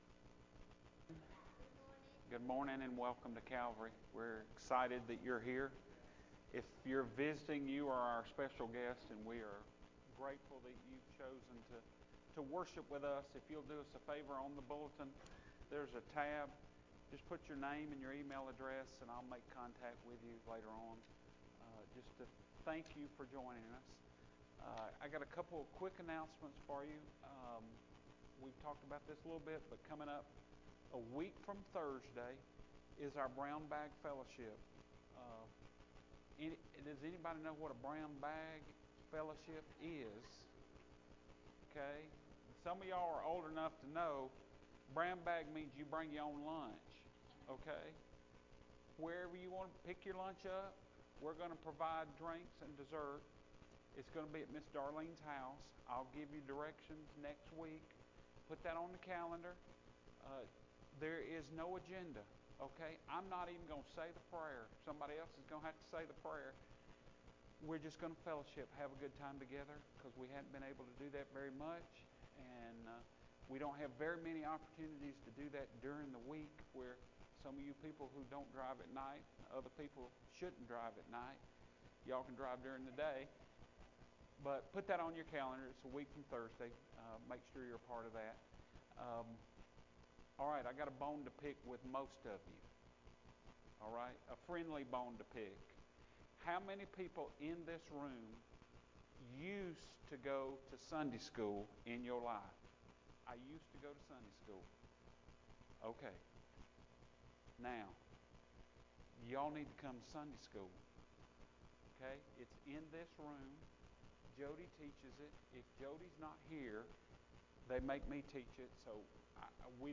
Full Service Audio